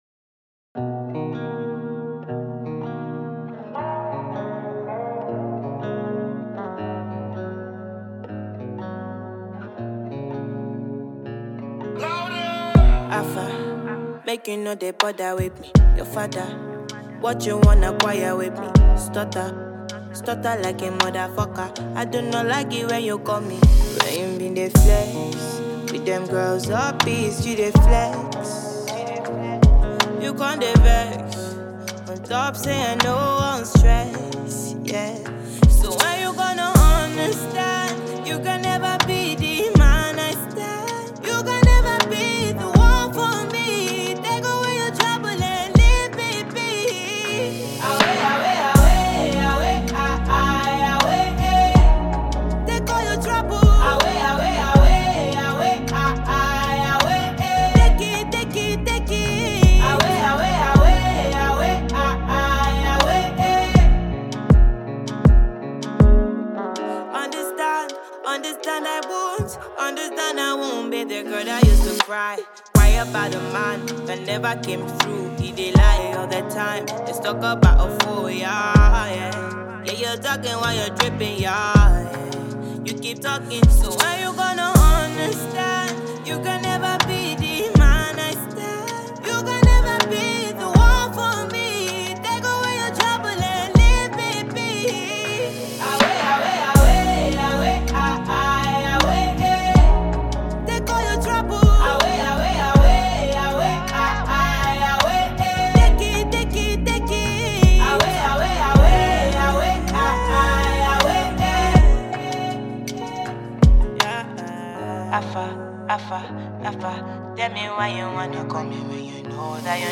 female act
melodious single